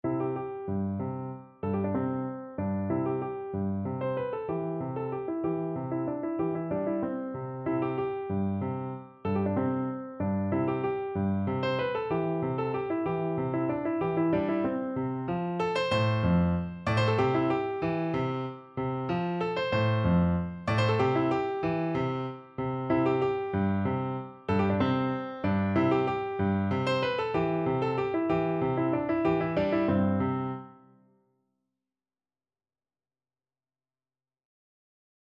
Piano version
No parts available for this pieces as it is for solo piano.
Poco sostenuto .=c.63
3/4 (View more 3/4 Music)
Piano  (View more Easy Piano Music)
Classical (View more Classical Piano Music)